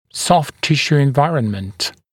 [sɔft ‘tɪʃuː en’vaɪrənmənt] [-sjuː][софт ‘тишу: эн’вайрэнмэнт] [-сйу:]окружающие мягкие ткани, состояние мягких тканей